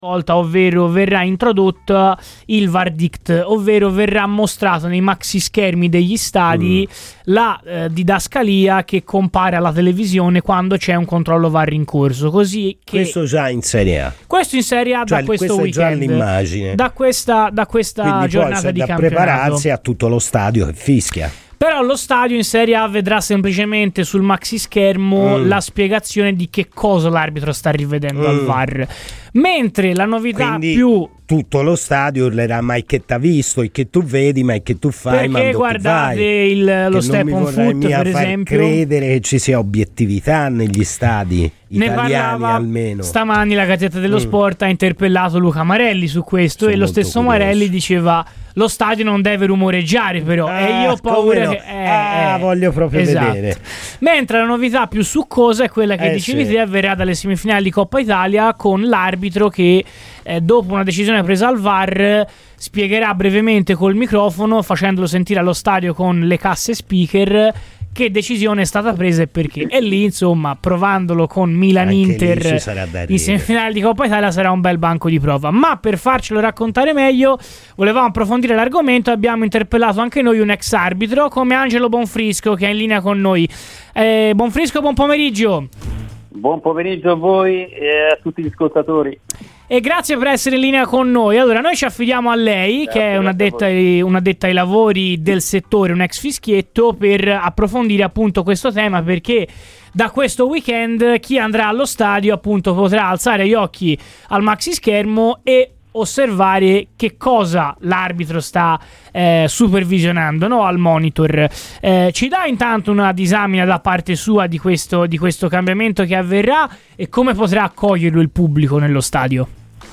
è intervenuto a Radio Firenzeviola durante la trasmissione "Palla al Centro" per parlare delle novità sul Var in Serie A e in Coppa Italia